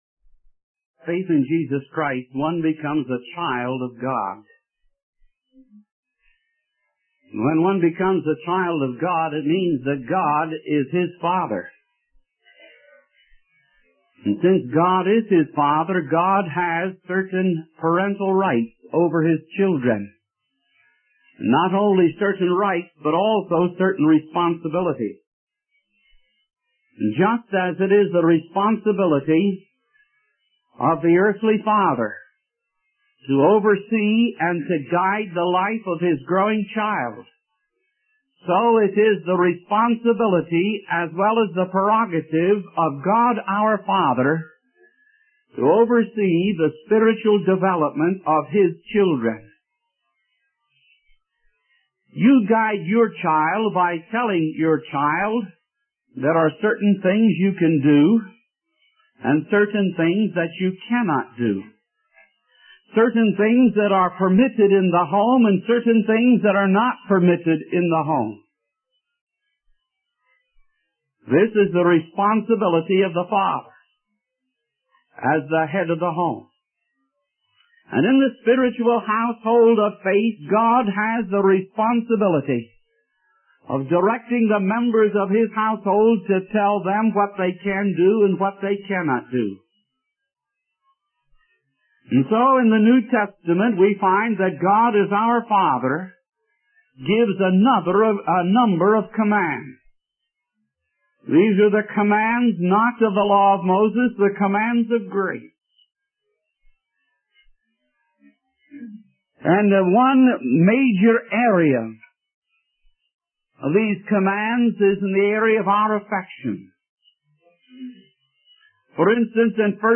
In this sermon, the preacher emphasizes the importance of showing love and care for others, especially within the family of God.